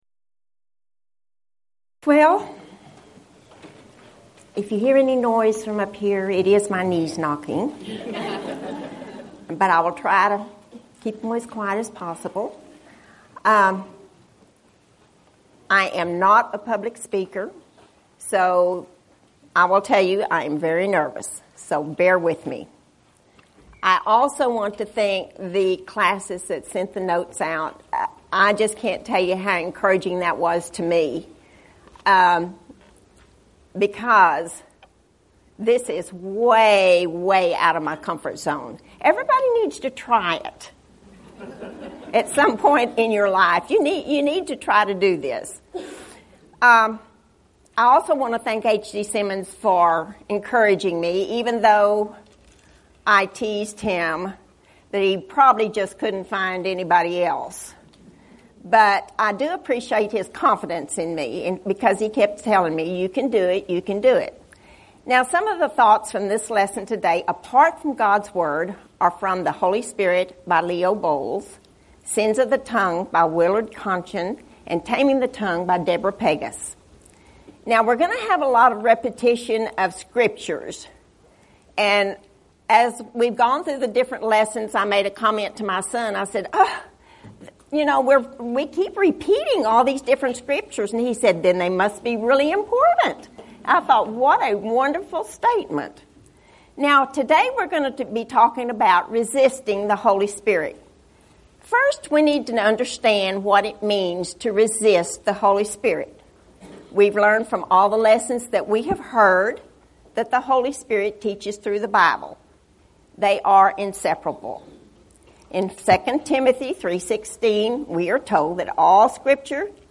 Event: 23rd Annual Gulf Coast Lectures
lecture